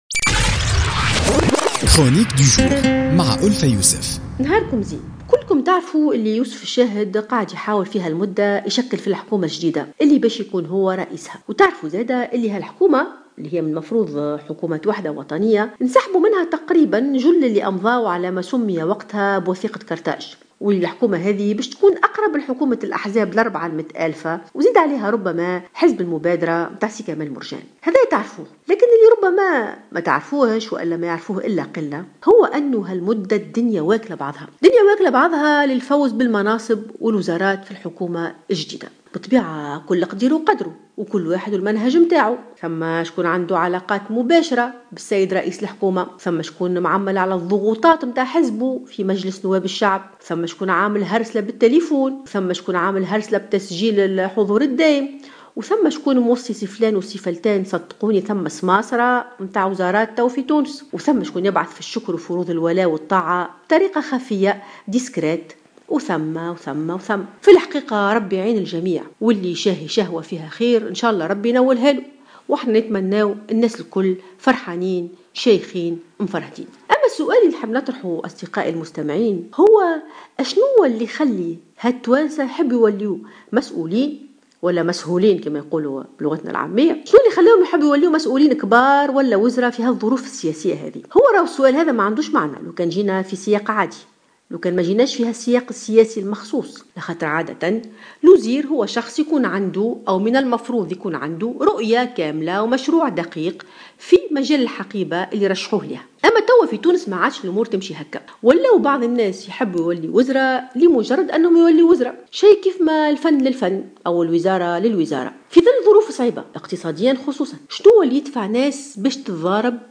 قالت الكاتبة ألفة يوسف في افتتاحيتها اليوم الخميس لـ "الجوهرة أف أم" إن هناك تدافع وسط مشاحنات في الكواليس للفوز بمنصب وزاري في الحكومة الجديدة بحسب ما بلغها من تسريبات.